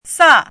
chinese-voice - 汉字语音库
sa4.mp3